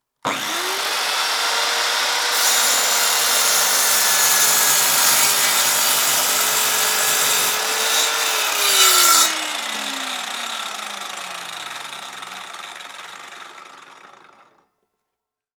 Scie-0960.wav